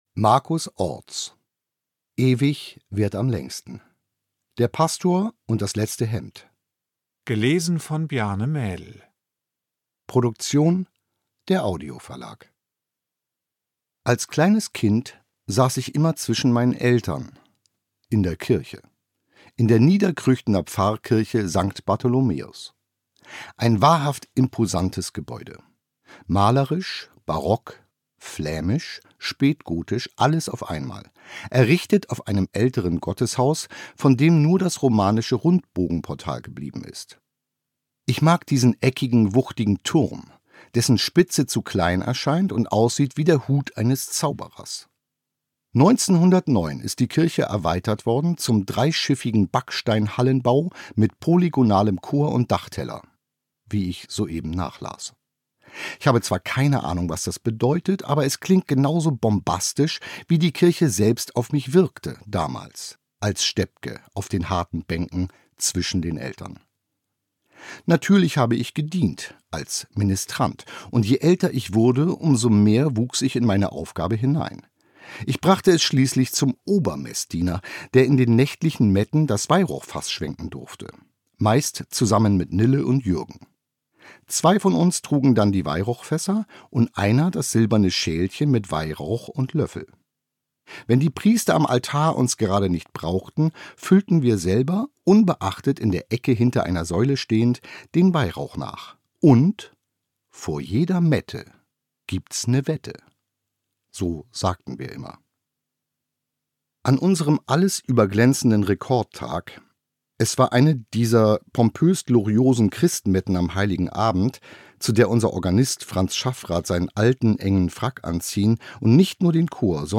Ungekürzte Lesung mit Bjarne Mädel (1 mp3-CD)
Bjarne Mädel (Sprecher)